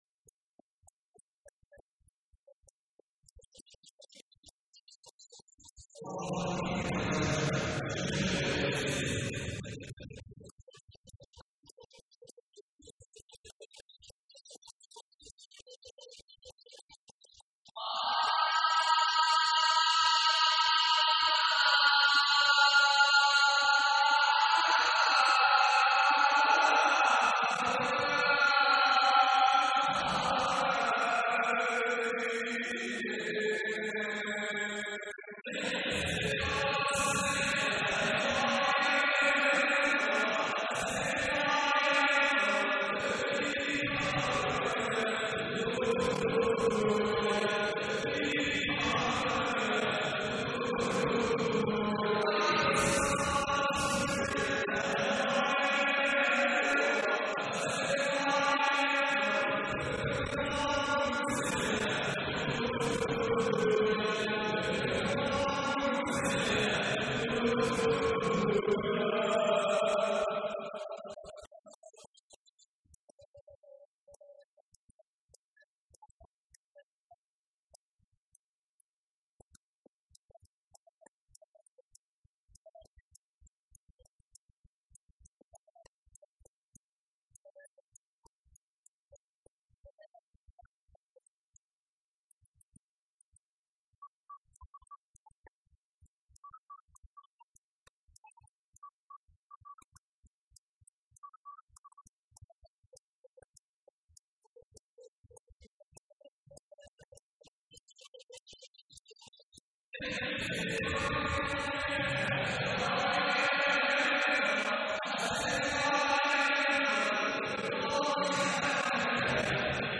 کرد موزیک